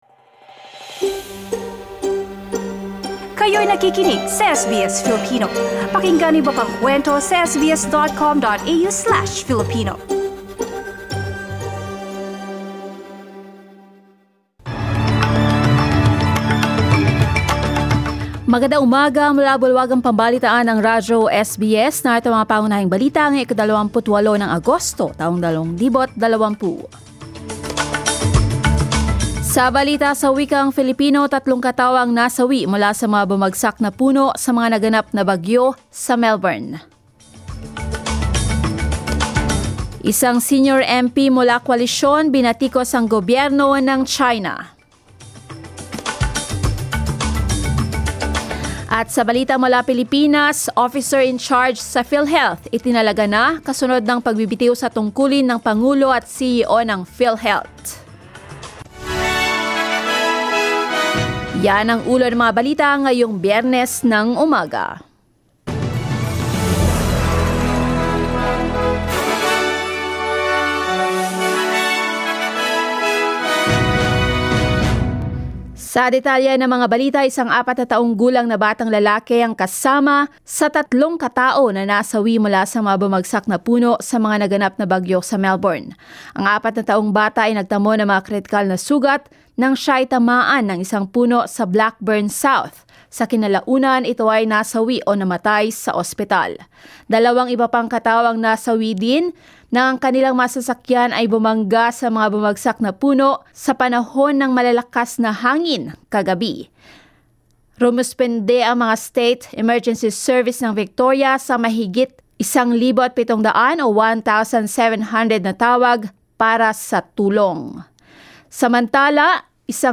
SBS News in Filipino, Friday 28 August